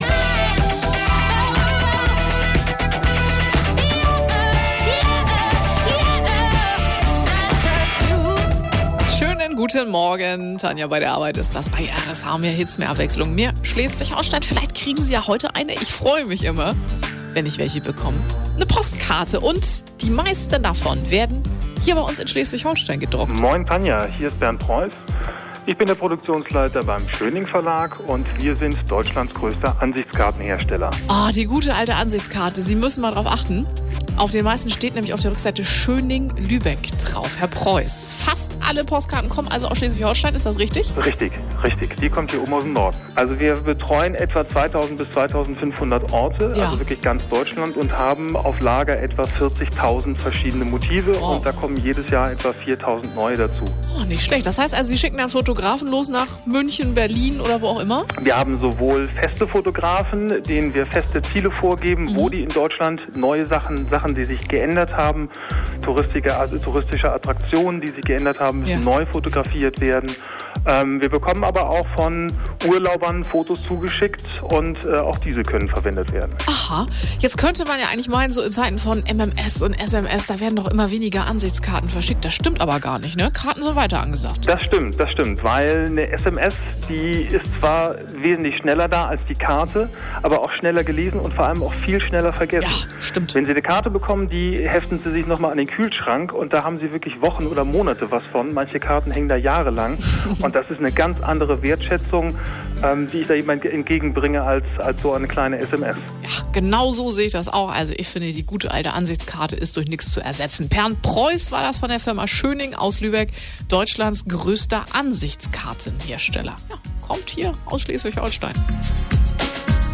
Dafür aber am letzten Dienstag schon mal im Radio bei RSH.
RSH-Interview als MP3 (ca. 2, 7 MB)